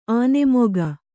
♪ ānemoga